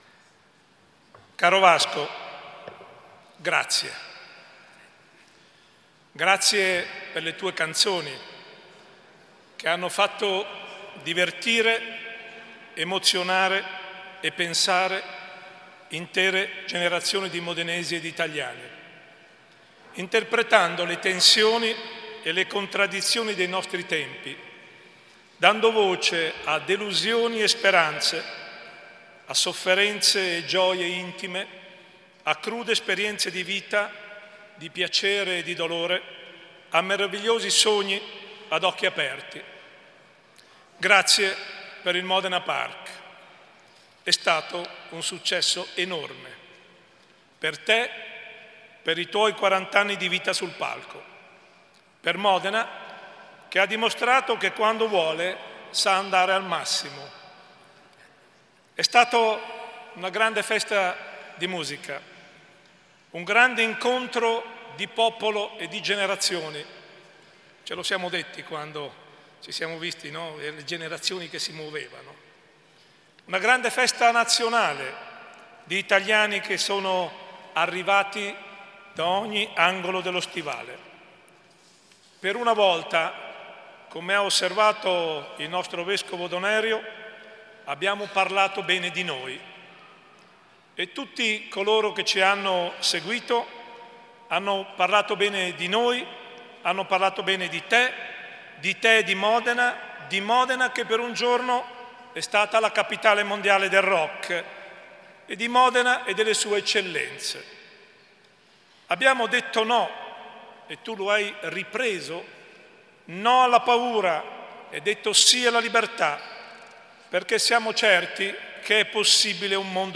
Gian Carlo Muzzarelli — Sito Audio Consiglio Comunale
Seduta del 17/01/2018 Interviene su: Conferimento della Cittadinanza Onoraria a Vasco Rossi